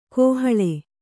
♪ kōhaḷe